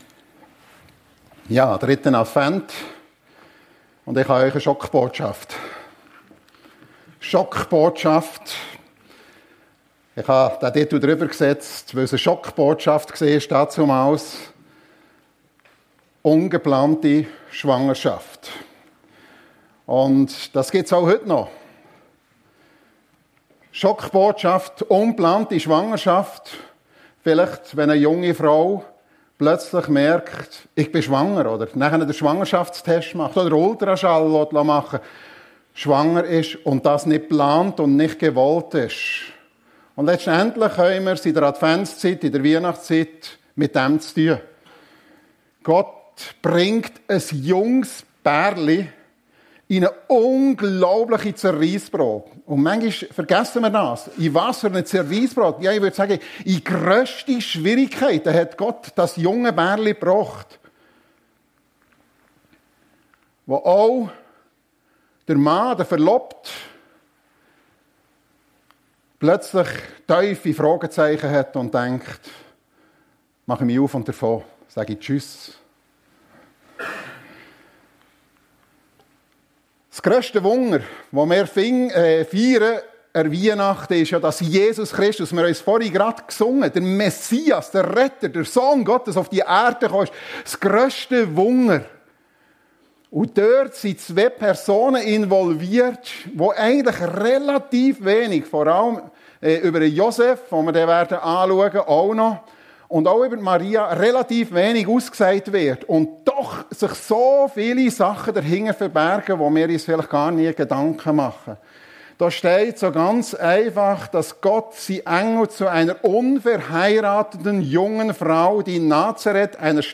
Schockbotschaft ungeplante Schwangerschaft ~ FEG Sumiswald - Predigten Podcast